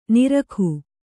♪ nirakhu